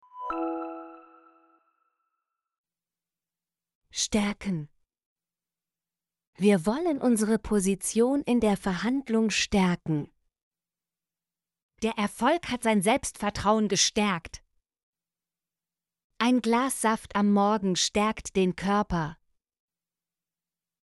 stärken - Example Sentences & Pronunciation, German Frequency List